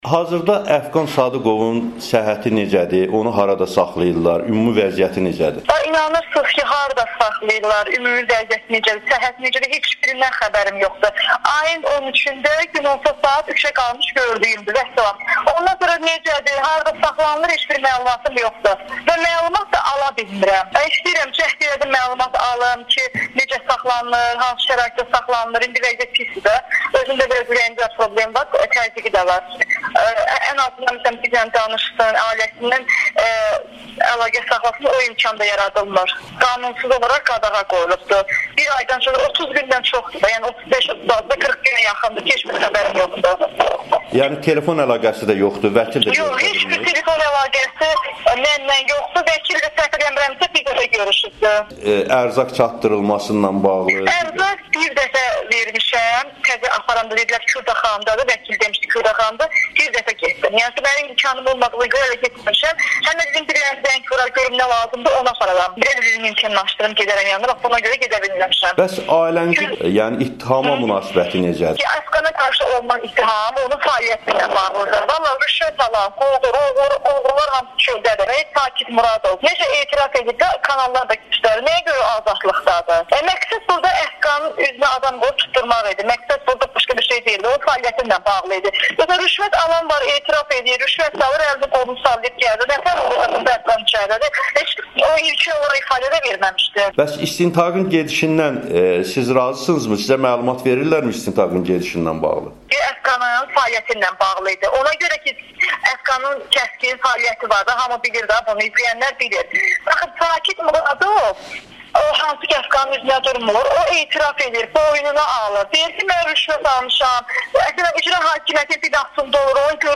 “Rüşvət aldığını etiraf edən azadlıqda gəzir, jurnalisti tutublar” - baş redaktorun həyat yoldaşı ilə müsahibə